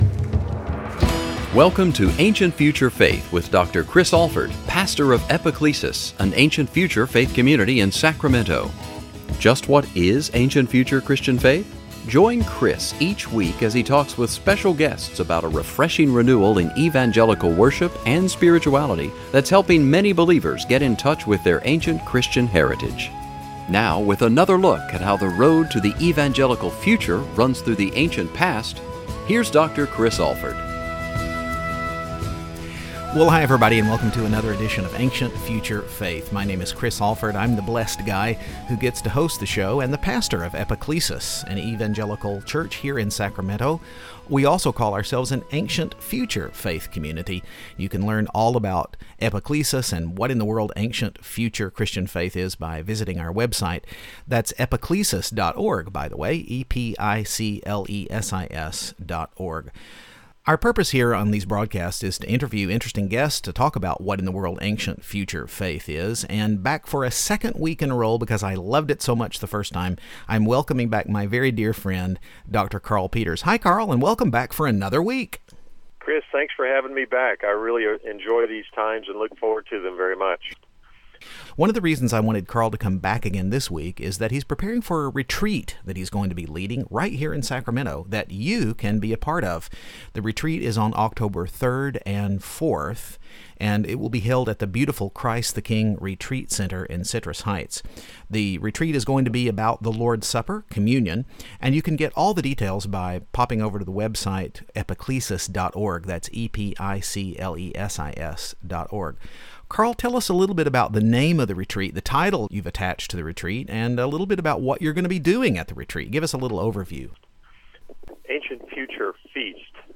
Join us here for part two of the conversation.